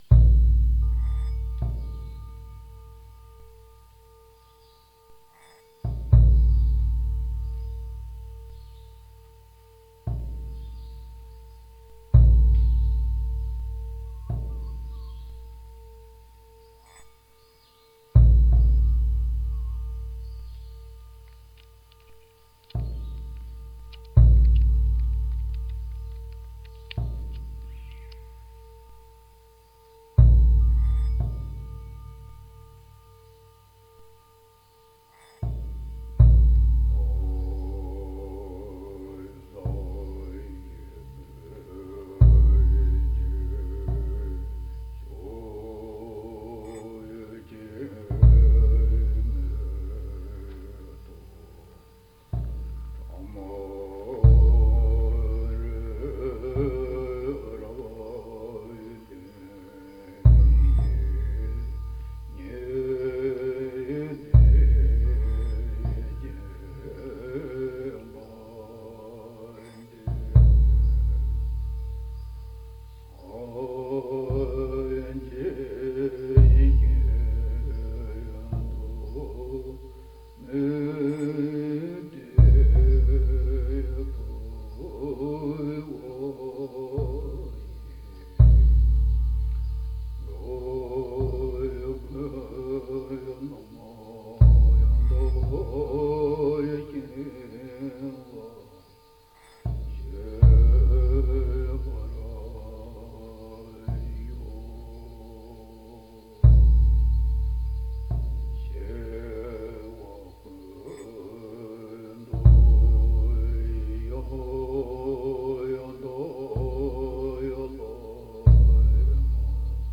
* Musique d'ambiance * (mieux vaut utiliser des écouteurs d'oreille plutôt que les enceintes basiques de l'ordi/téléphone, il y a des basses dans la piste audio) Les compagnons se résignèrent à rentrer dans le temple, contre ou de leur plein gré.